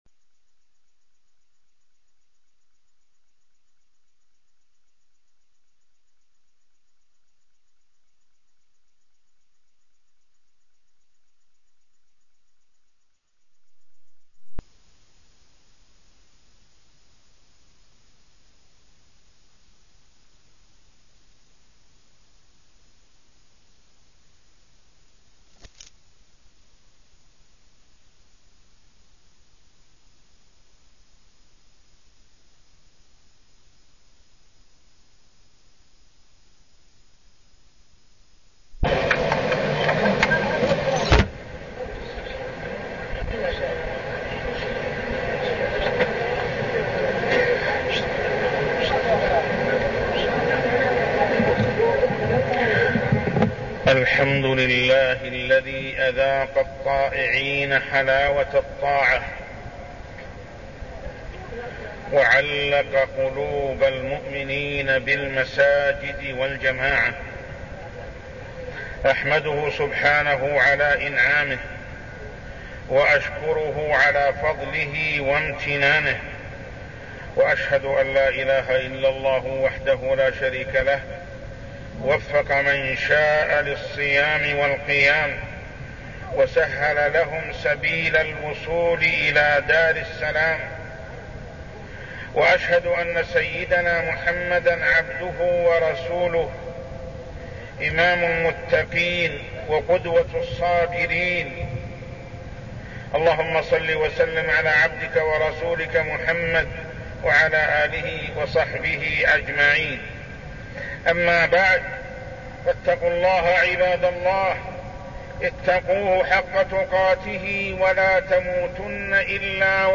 تاريخ النشر ١٢ رمضان ١٤١٣ هـ المكان: المسجد الحرام الشيخ: محمد بن عبد الله السبيل محمد بن عبد الله السبيل حقيقة العبادة